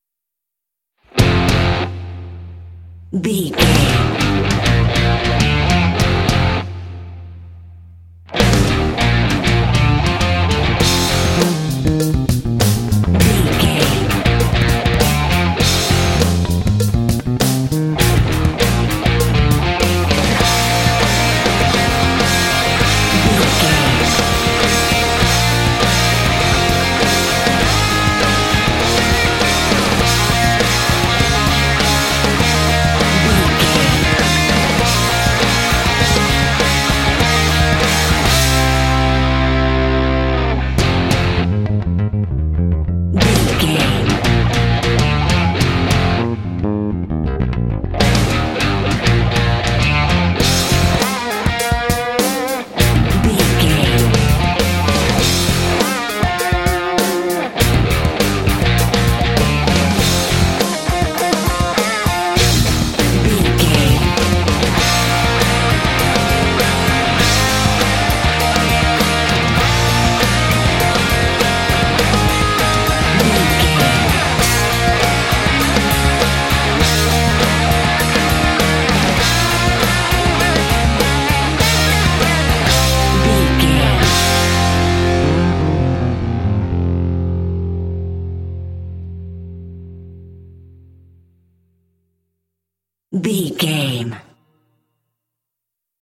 Epic / Action
Fast paced
Aeolian/Minor
driving
heavy
groovy
lively
energetic
drums
electric guitar
bass guitar
rock
heavy metal
classic rock